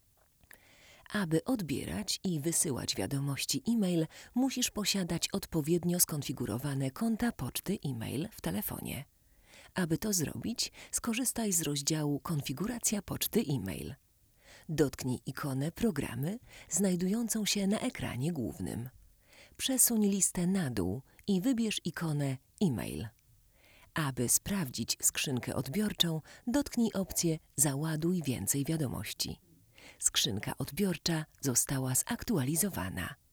Nagranie wokal żeński